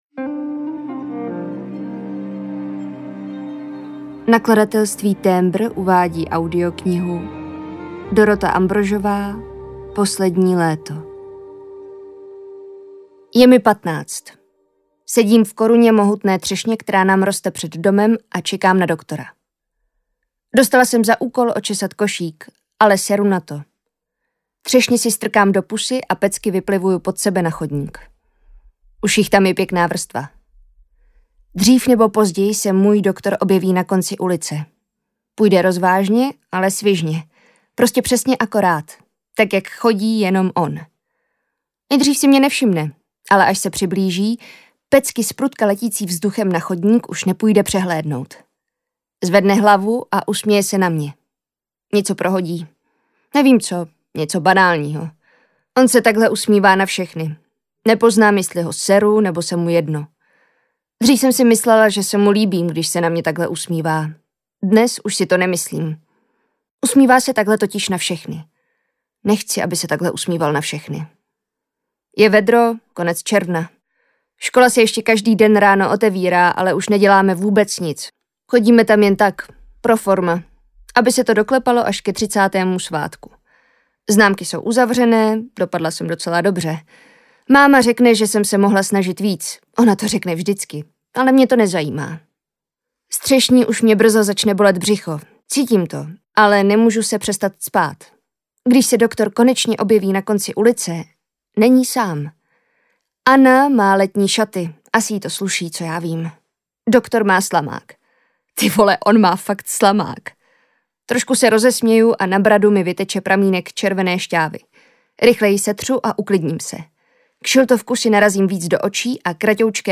Poslední léto audiokniha
Ukázka z knihy
Natočeno ve studiu All Senses Production s. r. o.